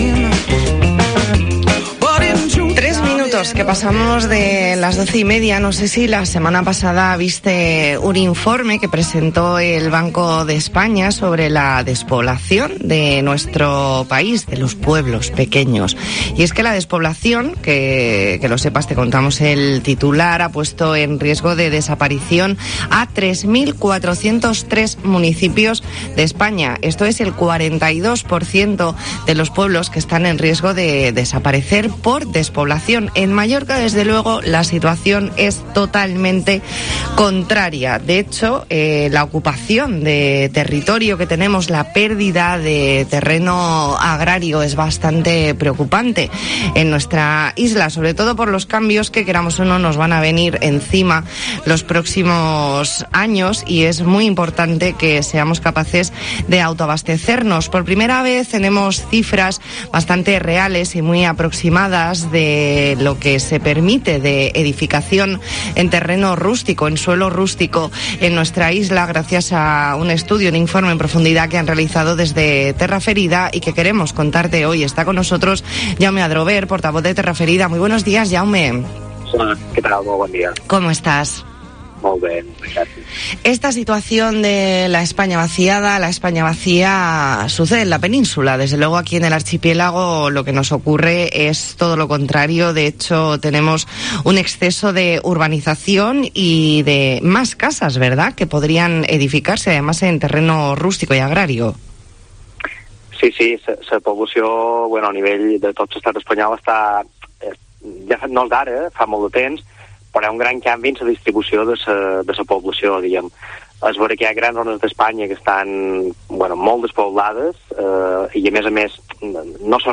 Entrevista en La Mañana en COPE Más Mallorca, lunes 17 de mayo de 2021.